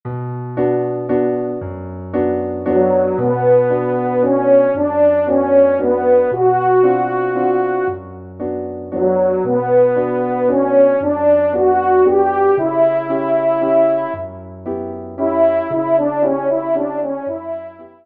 Valse lente Auteur
Genre : Divertissement pour Trompes ou Cors